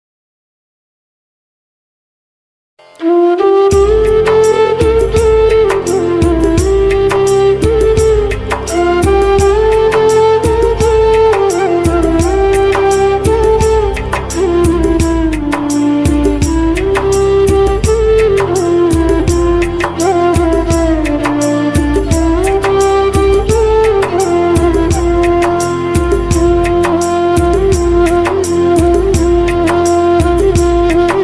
Bhakti Ringtone